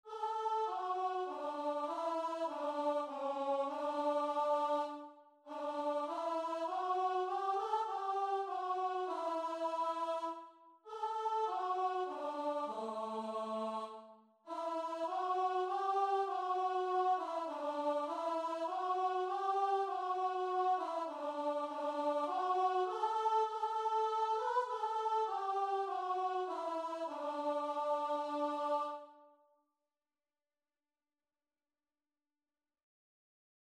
Christian
3/4 (View more 3/4 Music)
Classical (View more Classical Guitar and Vocal Music)